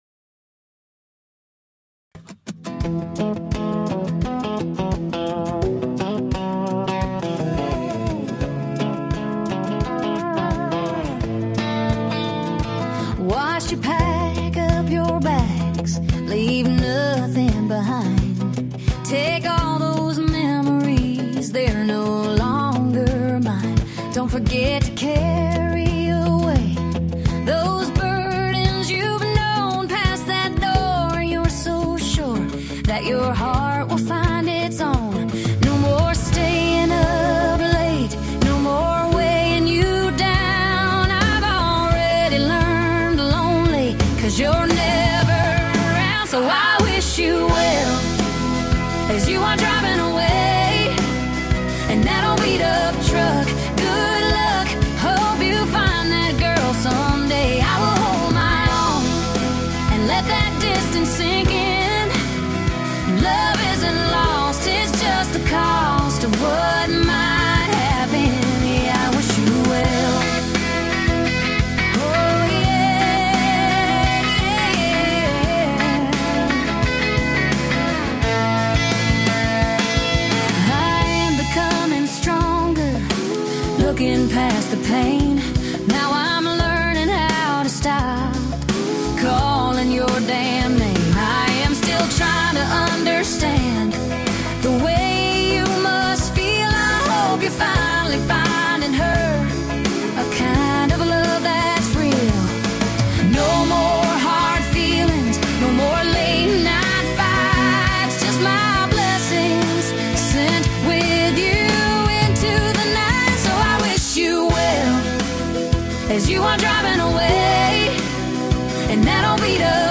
Country